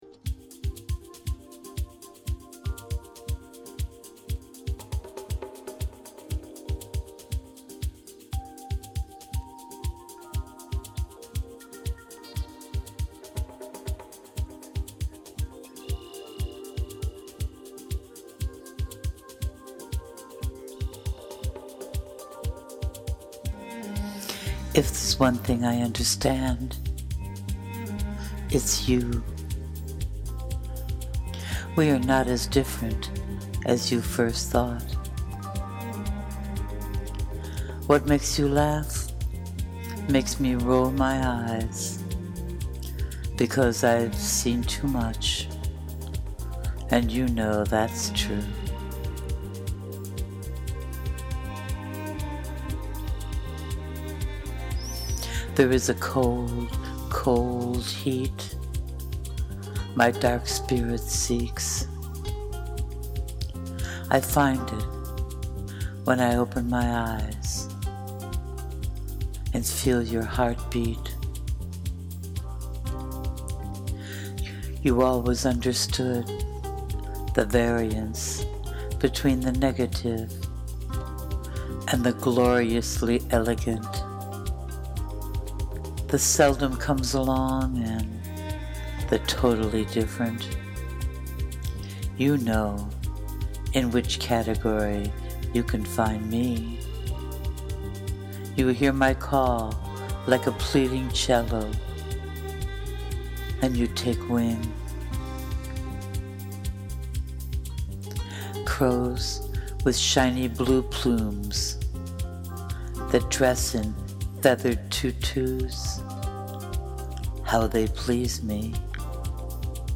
The depth and beauty in every line is simply divine and the way you delivered it was so truly magical.
It’s like a chant of magic, so thrilling ❤